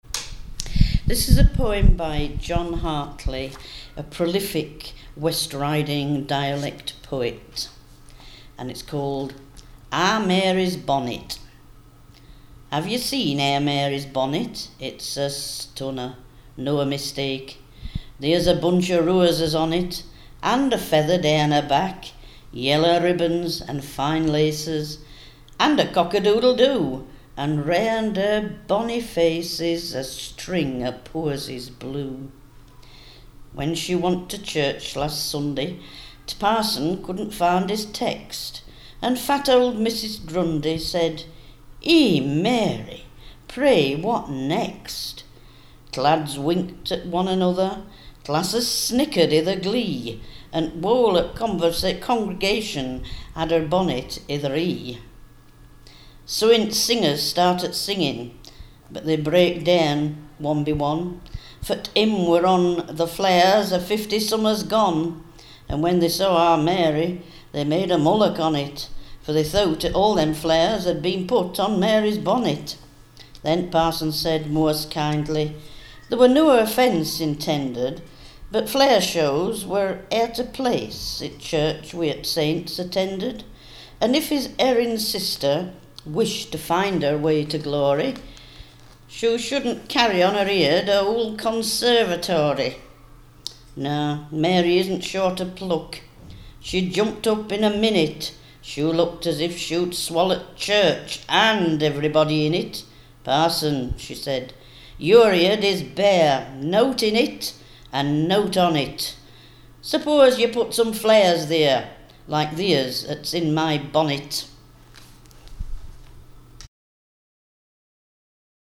Ahr Mary's Bonnet - a Yorkshire dialect poem by John Hartley